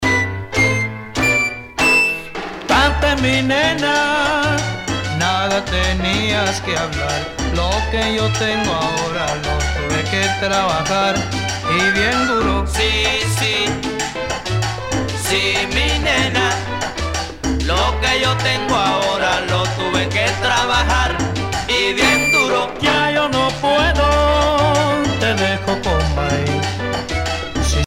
danse : mambo